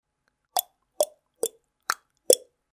Звуки цоканья языком
Звук цокання язиком у роті